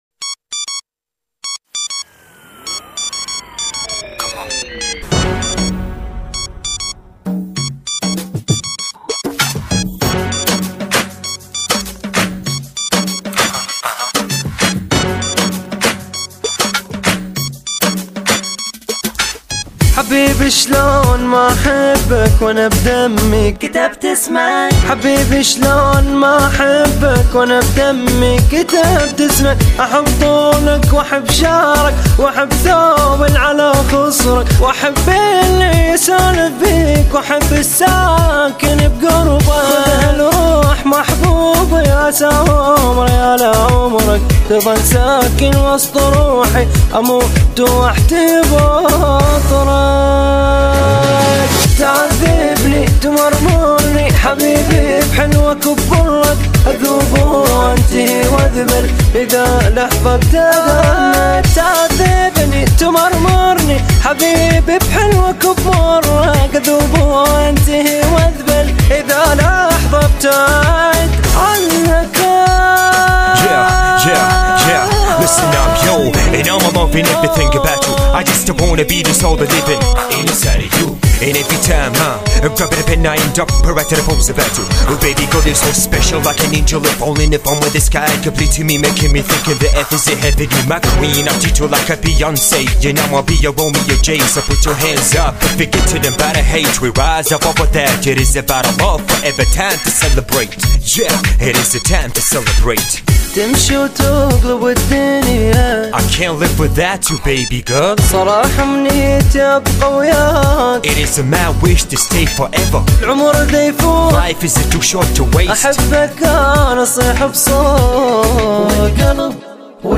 توزيع جديد